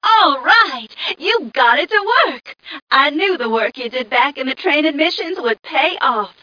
1 channel
mission_voice_m1ca023.mp3